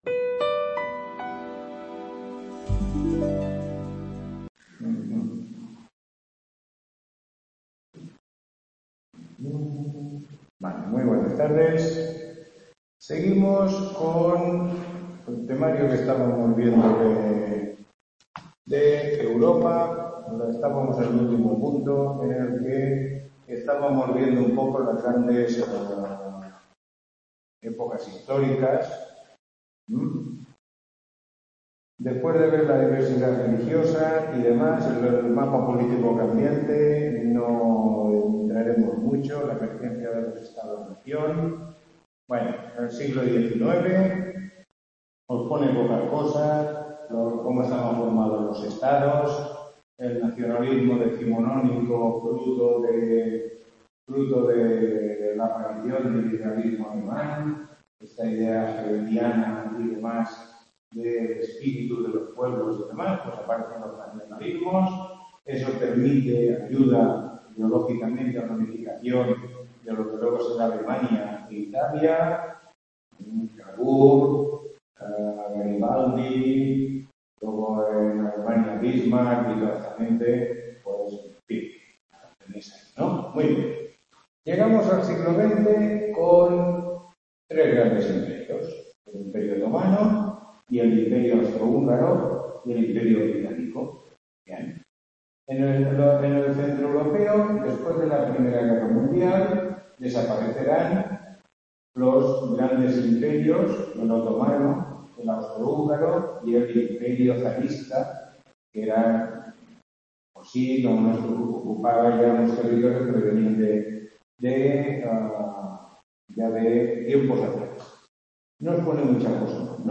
Tutoría 03